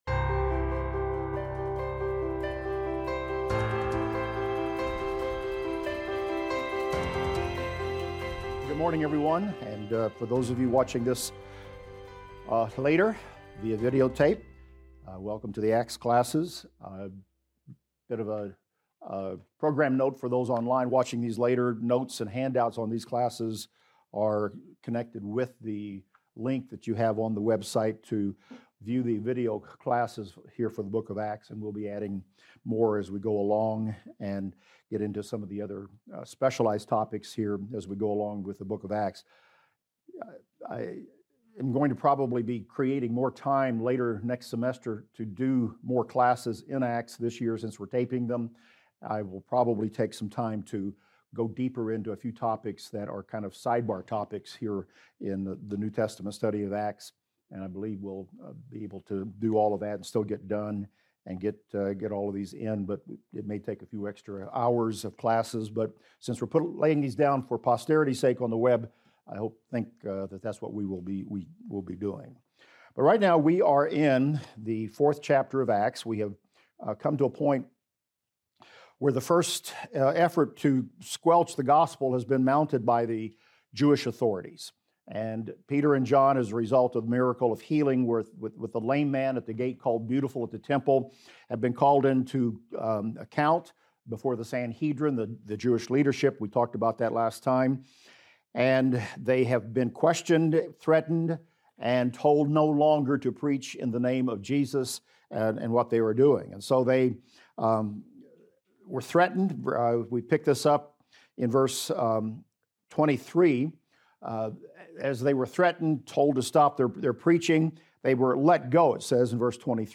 In this class we will discuss Acts 4:23-37 and talk about the importance of friendships, being bold in prayer and showing compassion to others.